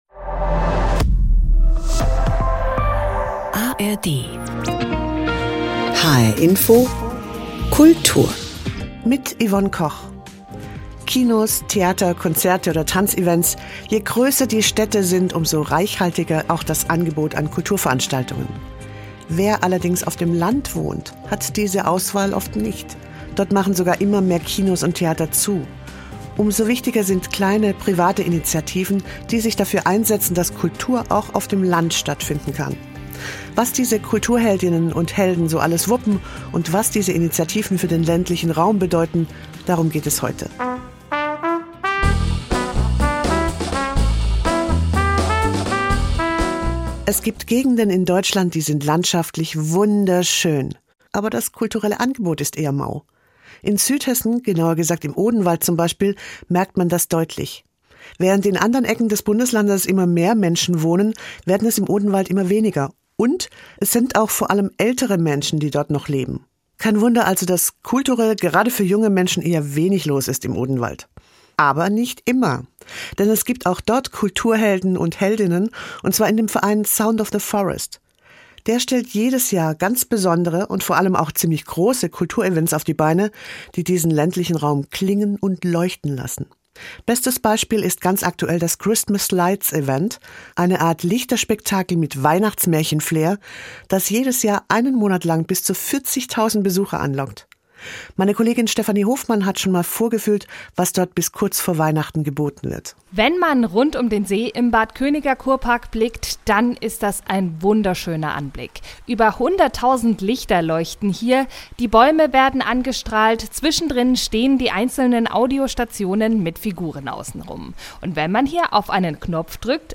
1 Stimme der Stummen - EKD-Ratsvorsitzende Kirsten Fehrs im Gespräch 25:25